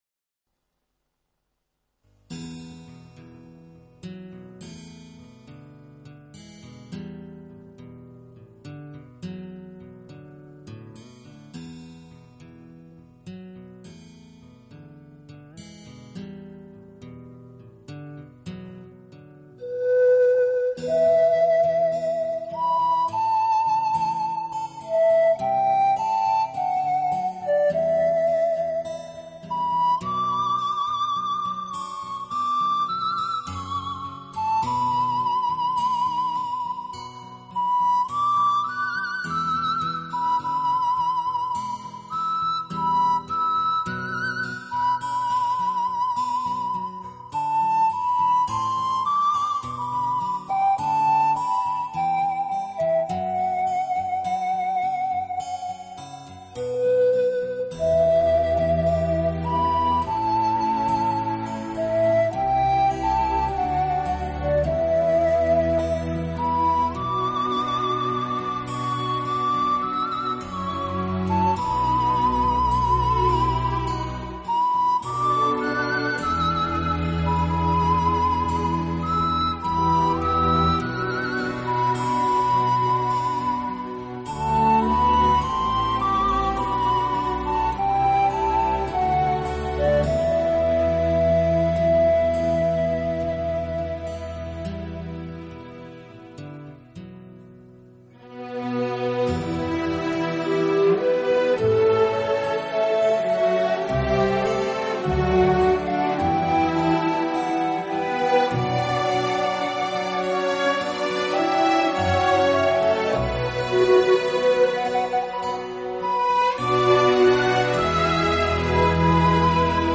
音乐类型：Instrumental